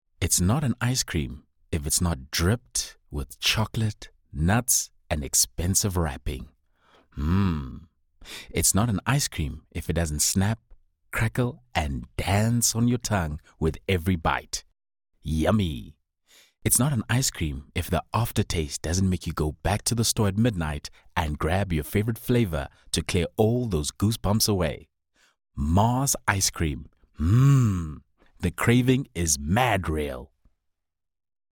Male
South African English , American English , British English
Approachable, Authoritative, Bright, Bubbly, Character
My accent is South African but i can switch to American and British accent as well.
Microphone: Rode NT2A
Audio equipment: Vocal Isolation booth